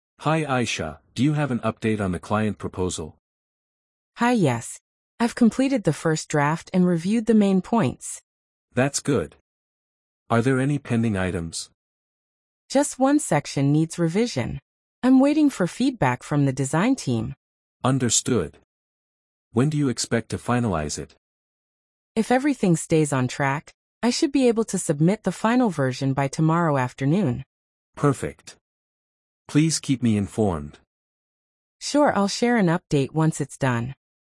🤝 A manager checks progress on an important proposal.